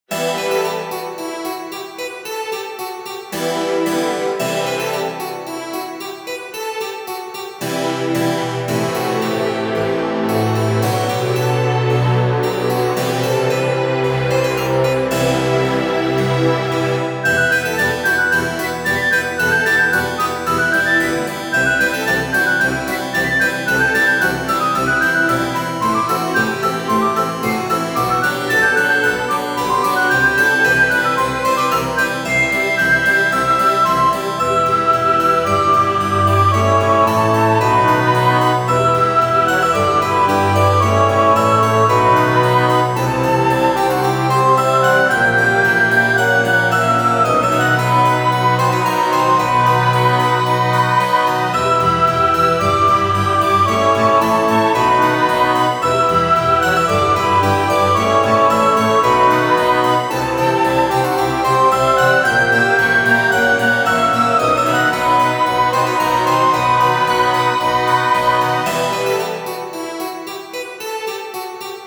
クラシカル